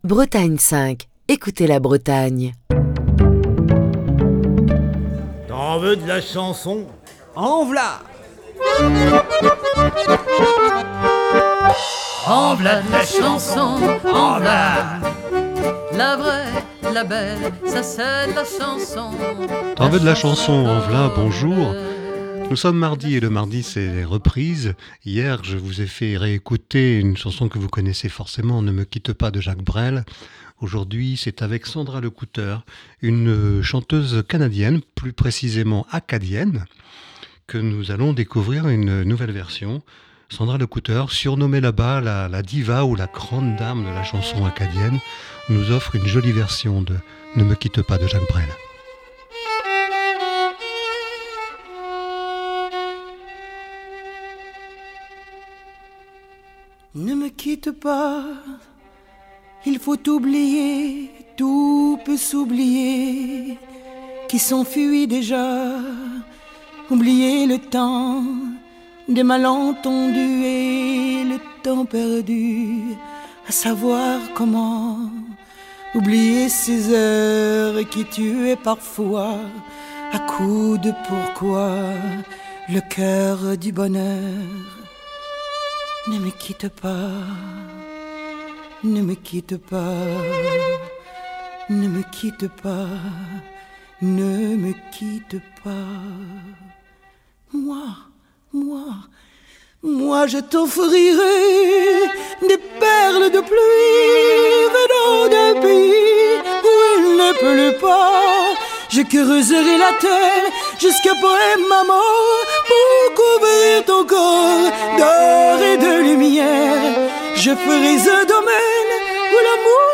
reprise